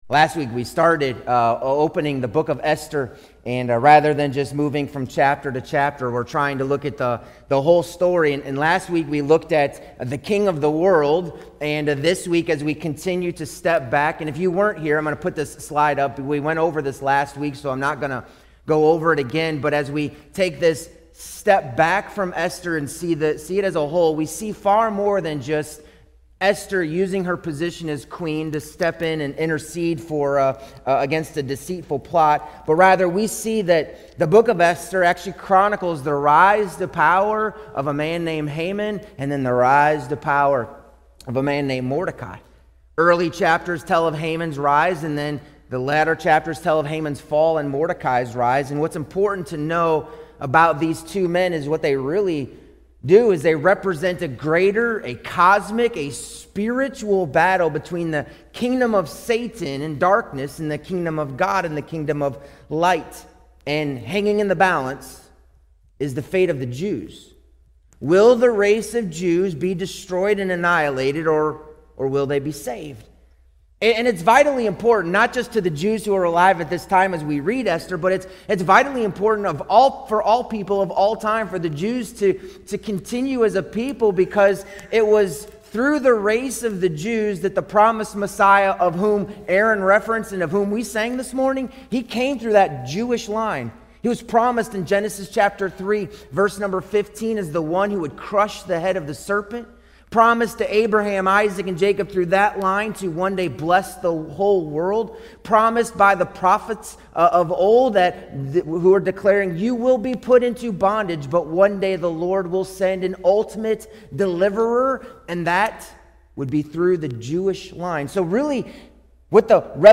Sermons | Mt. Carmel Regular Baptist Church